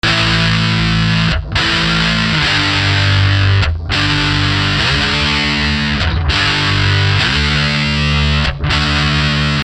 标签： 100 bpm Rock Loops Guitar Electric Loops 1.61 MB wav Key : Unknown
声道立体声